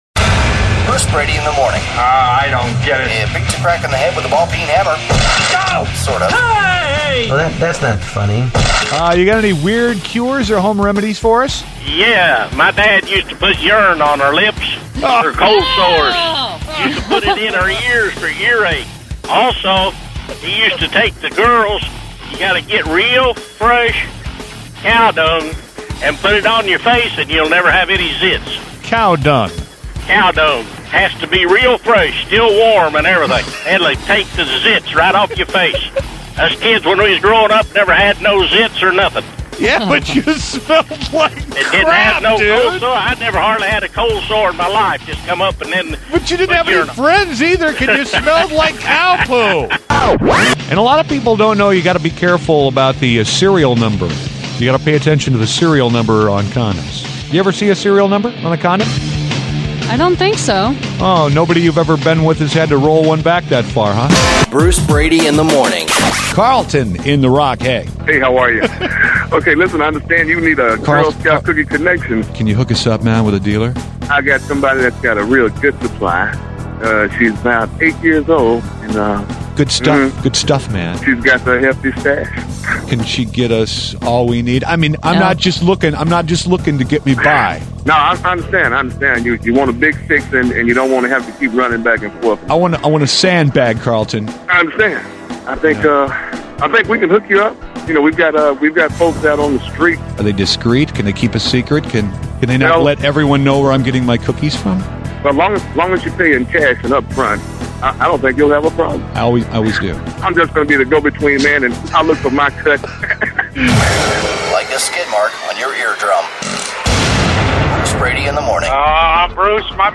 Lots of GREAT calls...
A native American called and said use urine for cold sores and use fresh cow dung on pimples.